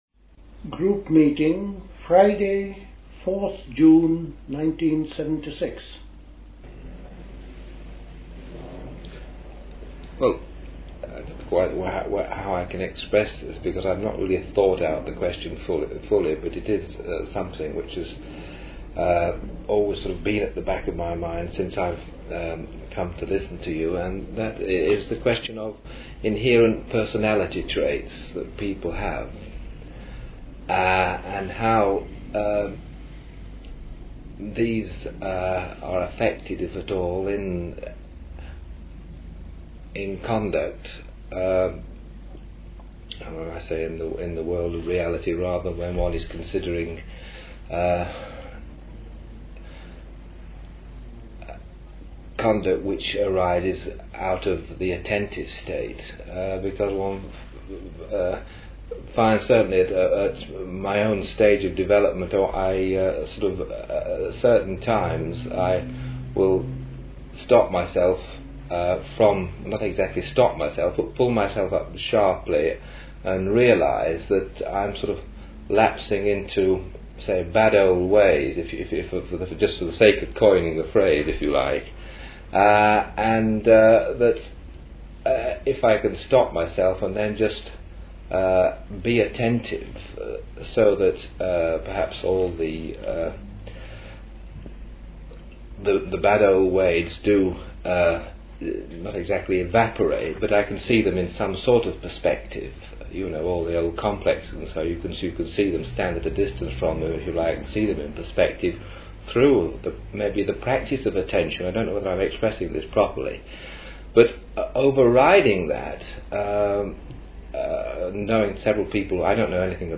Questions and answers concerning attentiveness and virtuous living. The way is from within, when the mind is empty of constructs, and is open and quiet. It is one’s own uniqueness which has to come to fruition.